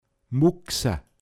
Pinzgauer Mundart Lexikon